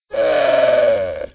c_camel_hit3.wav